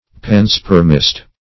Search Result for " panspermist" : The Collaborative International Dictionary of English v.0.48: Panspermatist \Pan*sper"ma*tist\, Panspermist \Pan"sper`mist\, n. (Biol.) A believer in panspermy; one who rejects the theory of spontaneous generation; a biogenist.